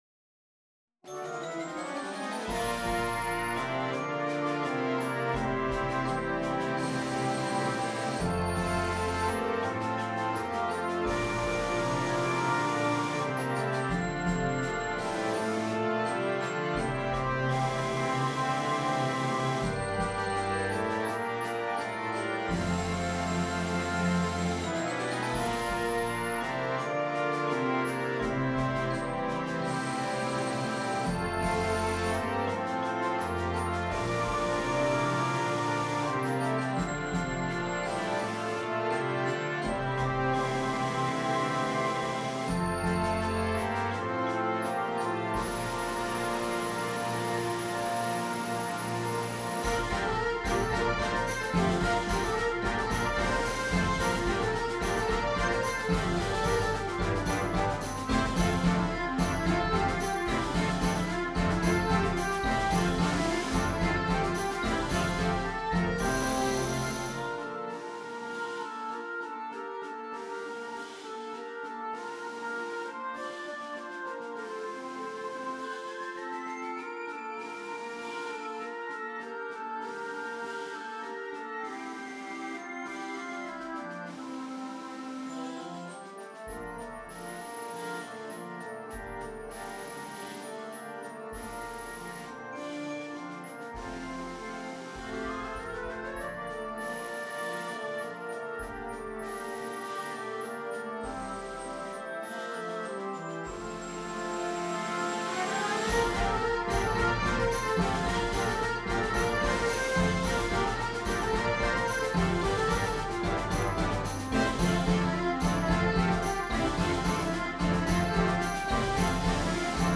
symphonic band